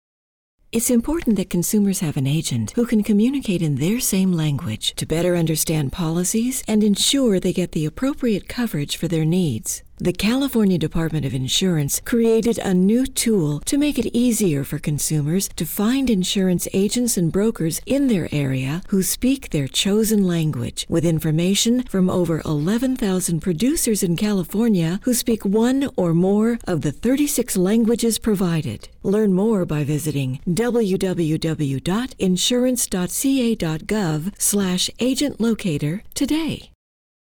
CDI Phone Hold Messages